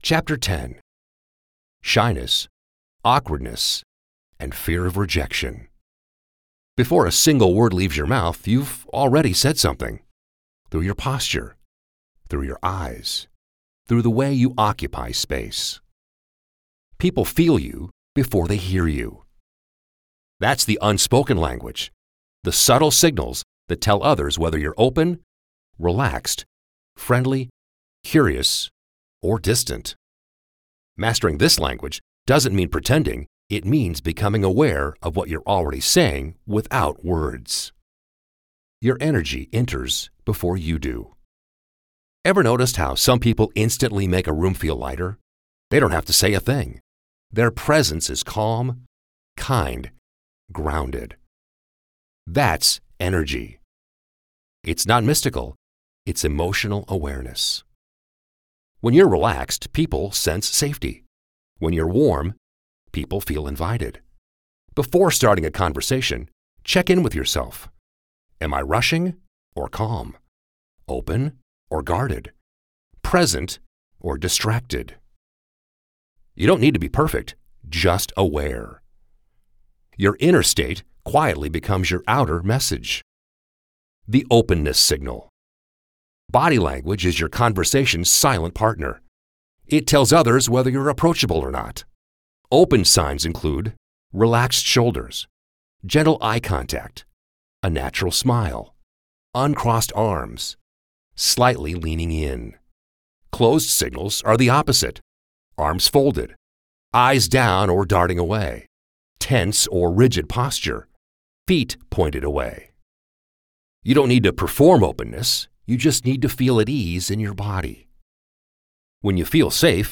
Adult (30-50) | Older Sound (50+)
Male Voice Over Talent
0418Demo_-_Audiobook.mp3